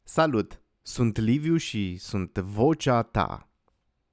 Voce pentru Reclame și Spoturi Radio/TV.
Demo Română brut